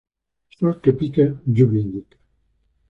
Pronúnciase como (IPA) /ˈsol/